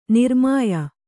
♪ nirmāya